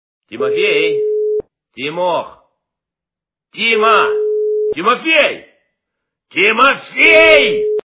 » Звуки » Именные звонки » Именной звонок для Тимофея - Тимофей, Тимох, Тима, Тимофей, Тимофей
При прослушивании Именной звонок для Тимофея - Тимофей, Тимох, Тима, Тимофей, Тимофей качество понижено и присутствуют гудки.